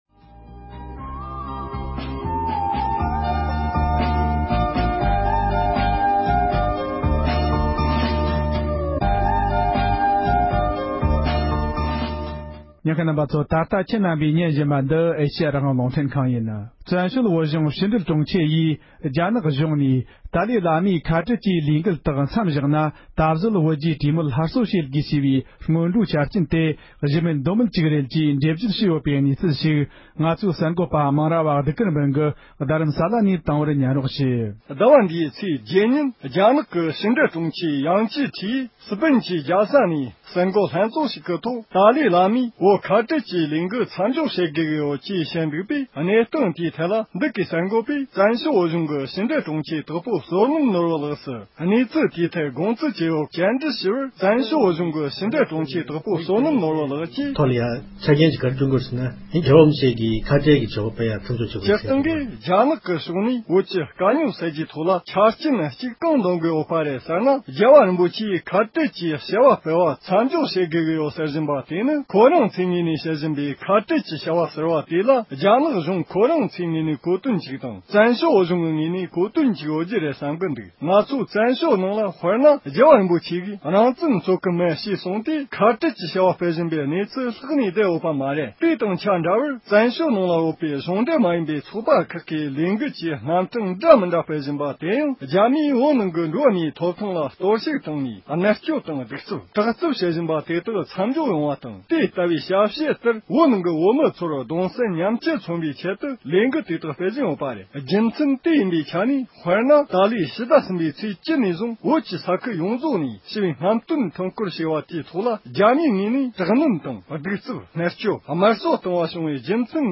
རྒྱ་ནག་གཞུང་ནས་༸གོང་ས་མཆོག་གིས་ཁ་བྲལ་གྱི་ལས་འགུལ་སྤེལ་གནང་གི་ཡོད་པ་བཤད་པའི་སྐོར་བཙན་བྱོལ་བོད་གཞུང་ཕྱི་དྲིལ་ལྷན་ཁང་གི་དྲུང་ཆེས་འགྲེལ་བརྗོད་གནང་འདུག
སྒྲ་ལྡན་གསར་འགྱུར།